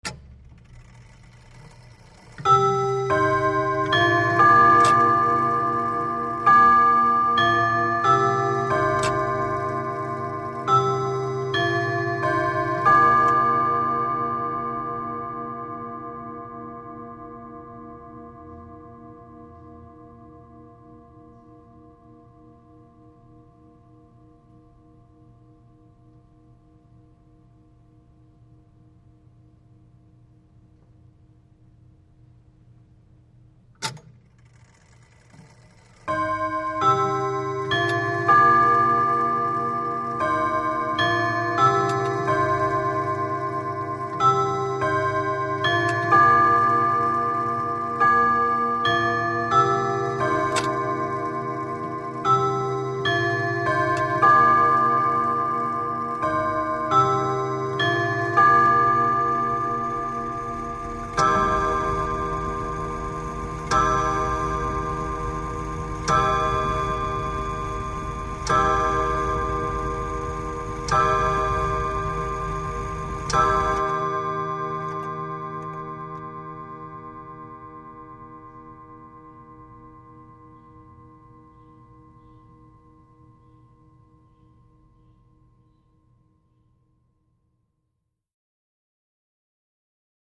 Grandfather Clock
Grandfather Clock, Chime Melody, Cu Perspective.